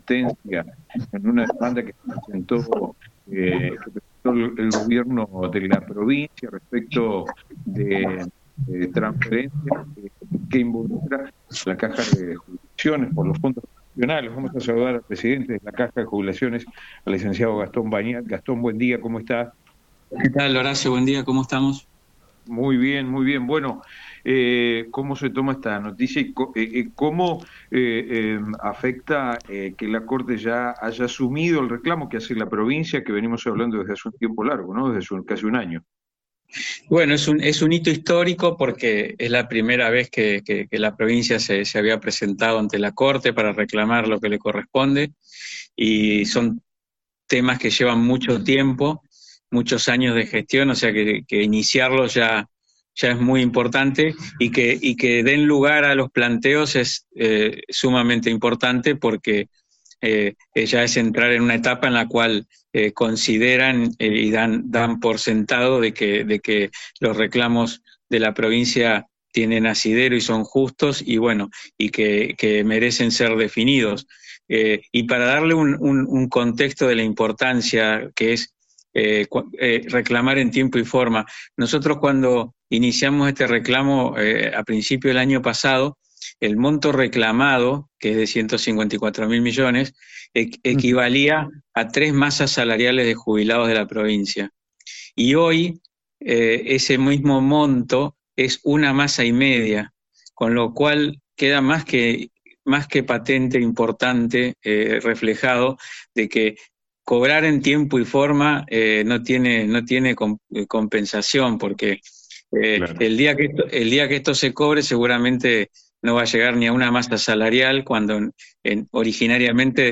En una entrevista exclusiva, el presidente de la Caja de Jubilaciones de Entre Ríos, el licenciado Gastón Bagnat, compartió su perspectiva sobre la trascendencia de esta noticia y el impacto que esta medida podría generar en las finanzas provinciales, así como en la estabilidad a largo plazo del sistema de jubilaciones.